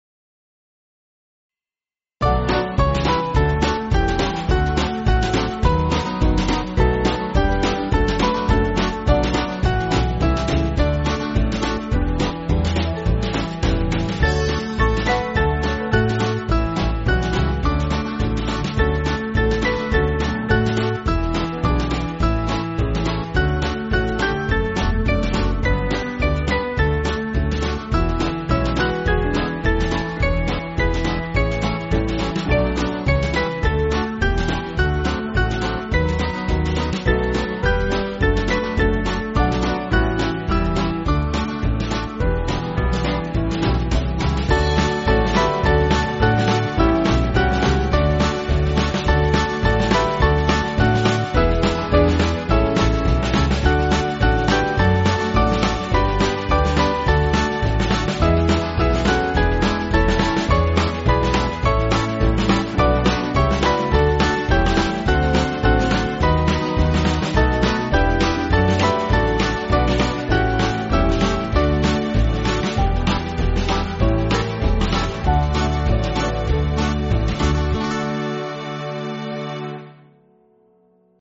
Kid`s club music
Small Band